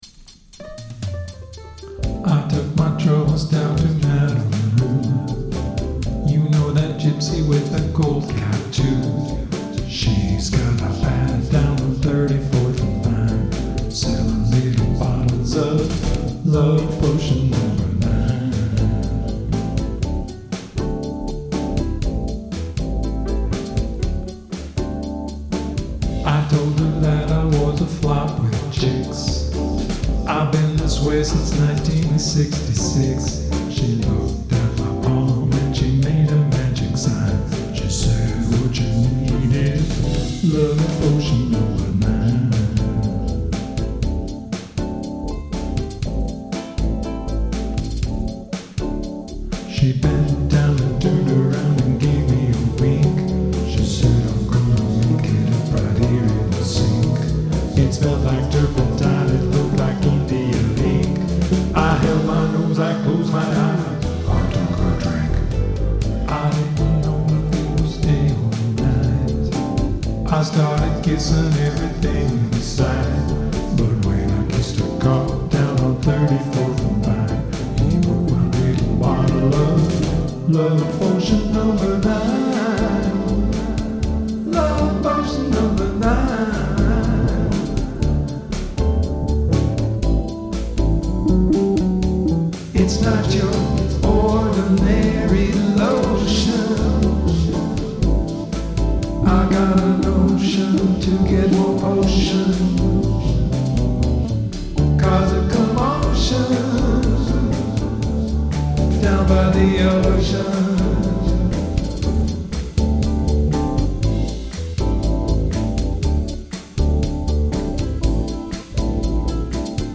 Percussion, synth bass, organ
Drums, ukulele, vocal, electric bass
added vocals, uke, ebass, vocoder